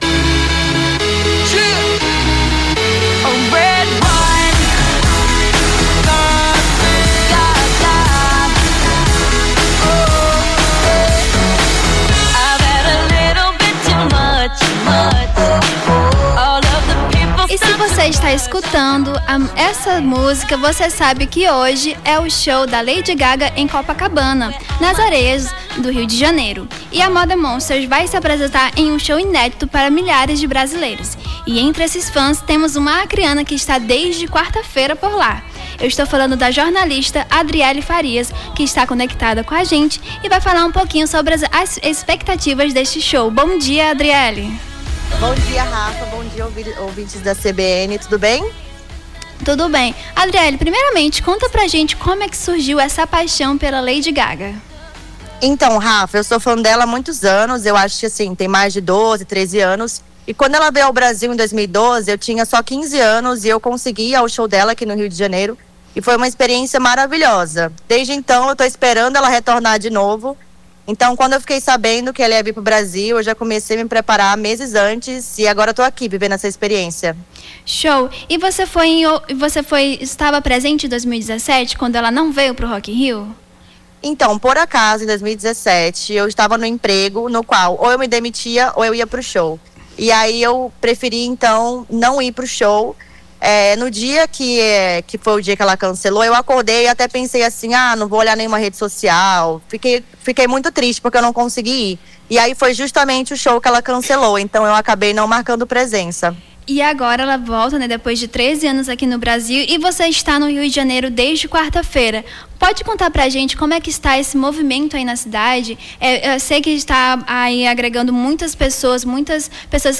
Fã acreana fala sobre as expectativas para o show da Lady Gaga em Copacabana - CBN Amazônia